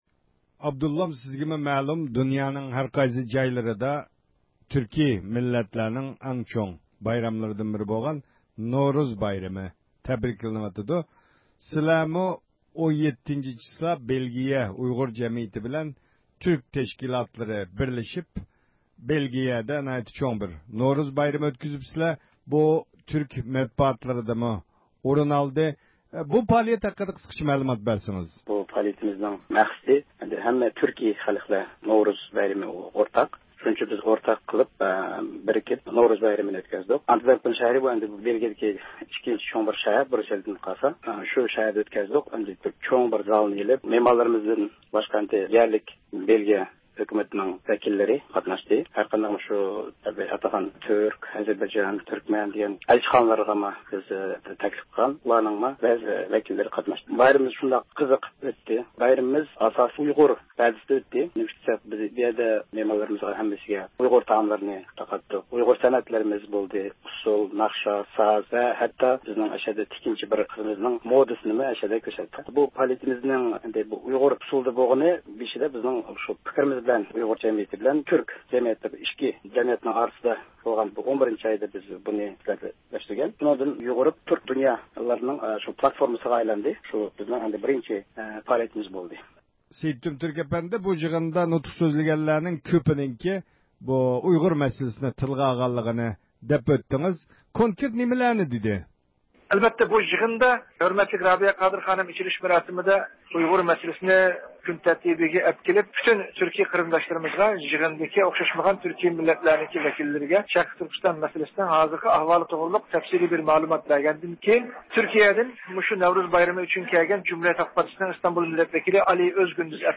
بىز بۇ پائالىيەتتىن كېيىنكى ھېسسىياتىنى سۆزلەپ بېرىش ئۈچۈن دۇنيا ئۇيغۇر قۇرۇلتىيى رەئىسى رابىيە قادىر خانىم بىلەن سۆھبەت ئۆتكۈزدۇق